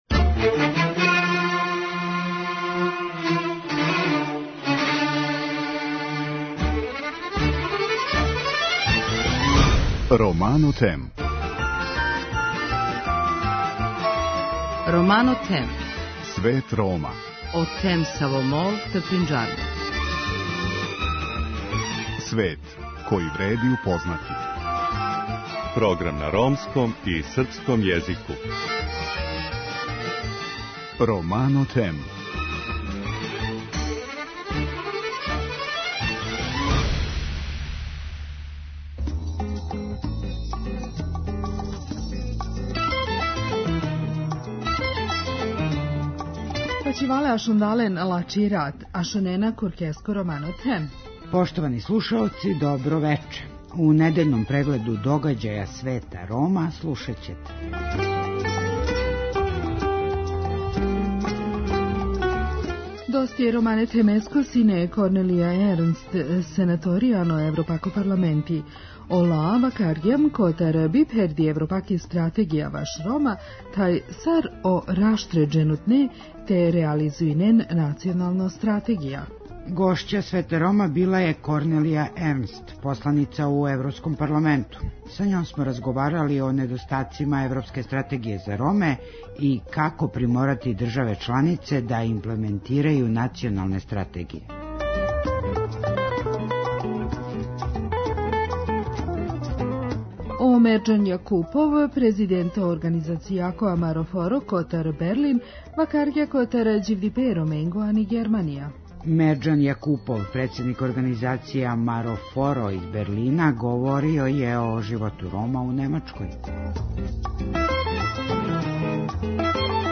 Гошћа Света Рома је Корнелија Ернст, бивша посланица у Европском парламенту. Са њом смо разговарали о недостацима европске стратегије за Роме и како приморати државе чланице да имплементирају националне стратегије.